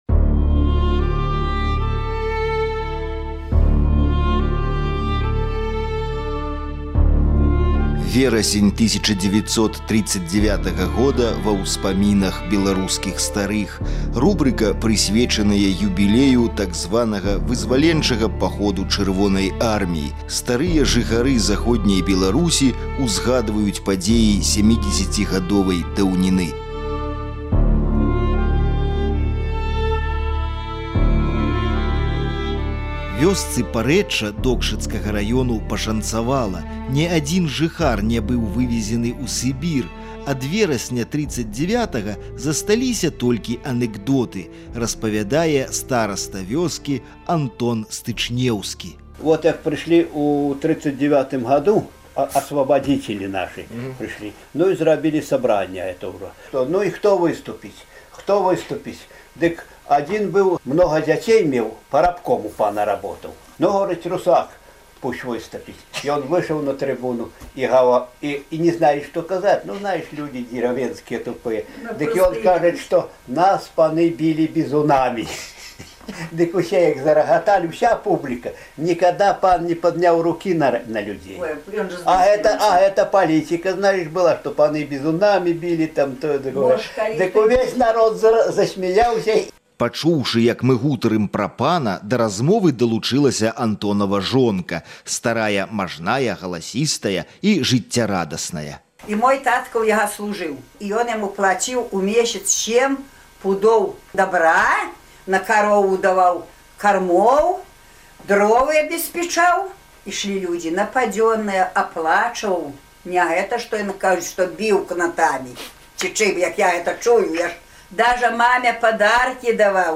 Успаміны відавочцаў пра верасень 1939 году.